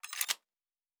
pgs/Assets/Audio/Sci-Fi Sounds/Weapons/Weapon 10 Foley 3.wav at master
Weapon 10 Foley 3.wav